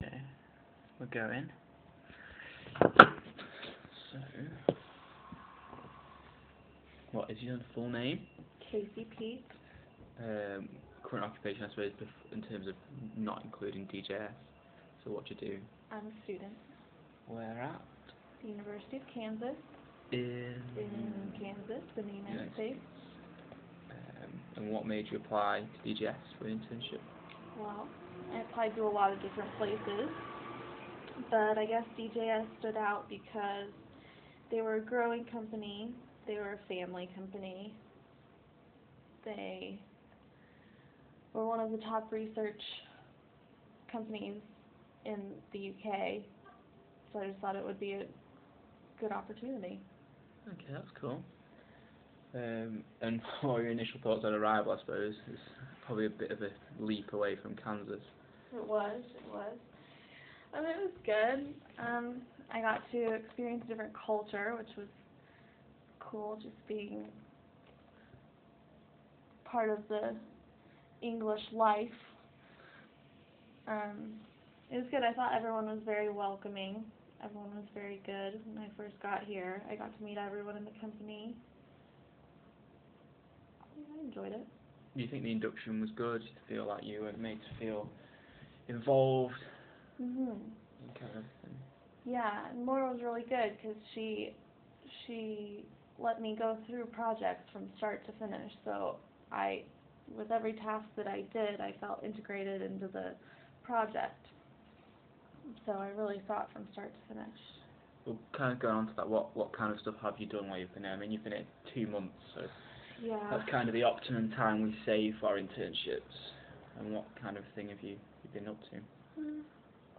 DJS Research Ltd_Post-Internship Interview_Market Research.mp3